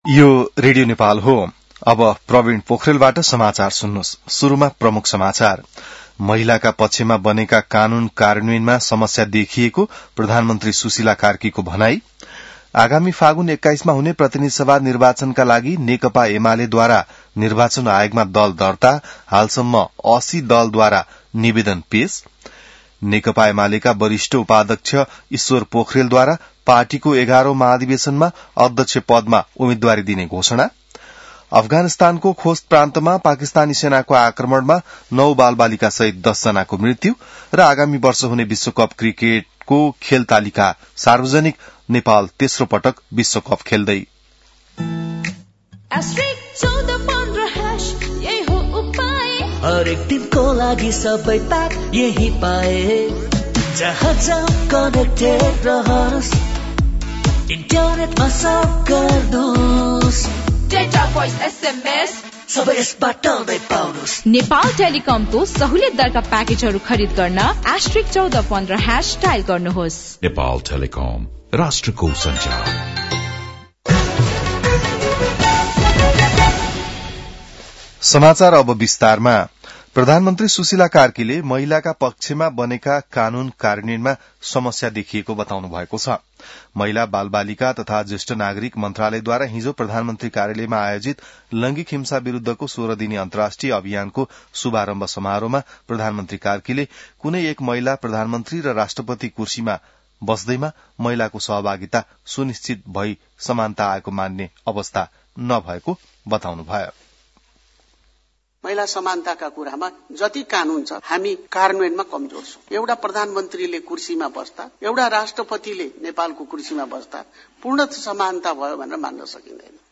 बिहान ७ बजेको नेपाली समाचार : १० मंसिर , २०८२